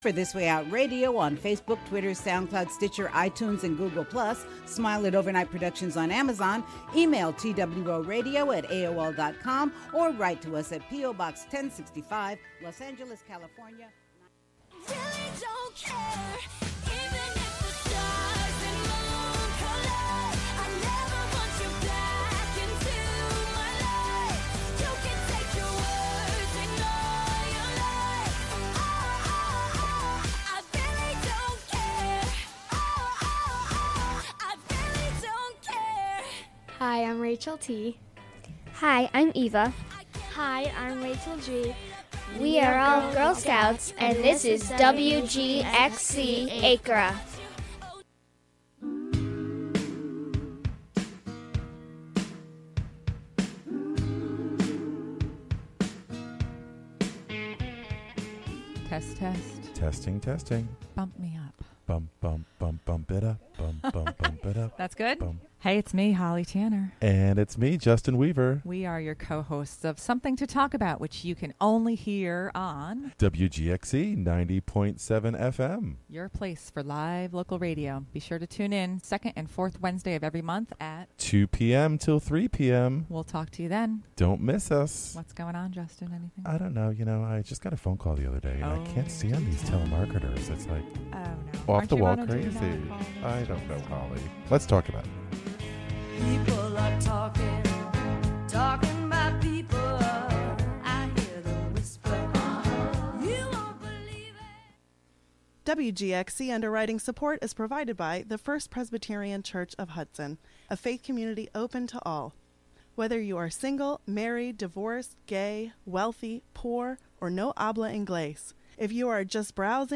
1930s and 1940s music